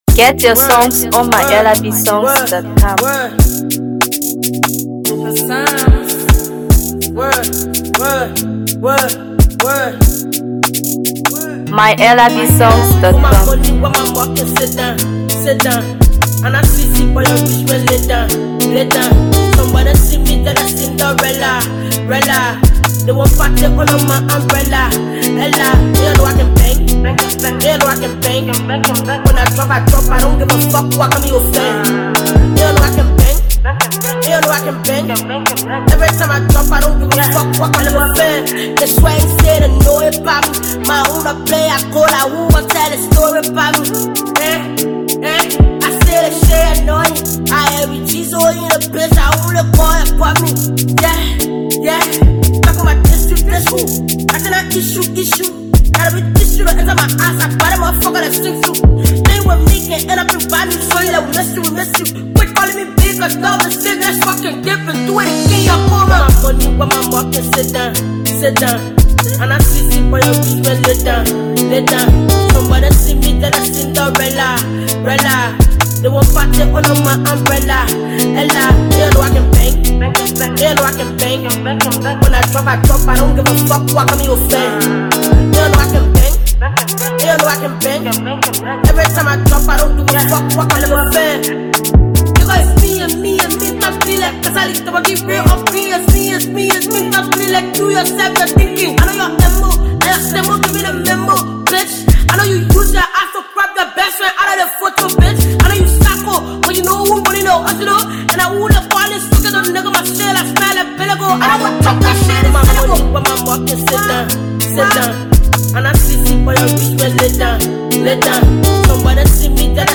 Known for her unique blend of Afrobeat, Dancehall, and Hipco